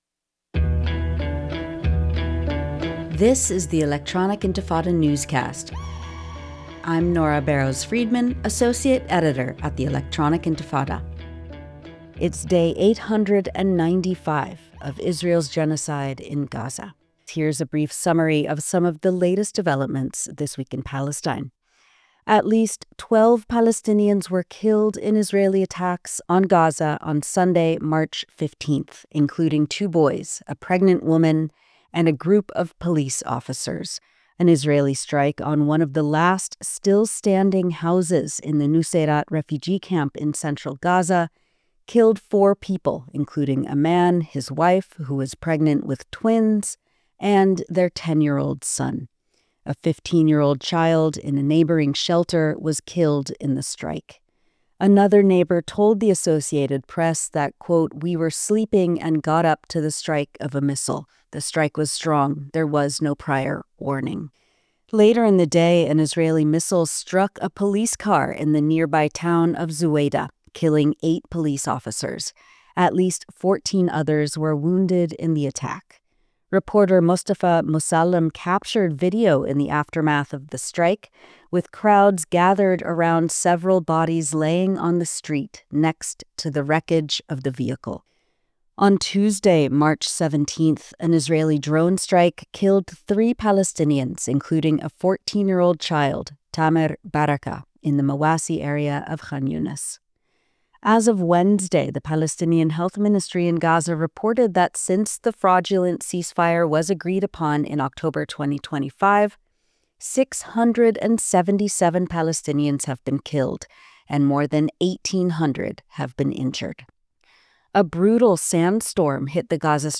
Subtitle: Palestinian news for the week of 19 March 2026 Program Type: Weekly Program